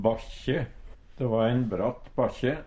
bakkje - Numedalsmål (en-US)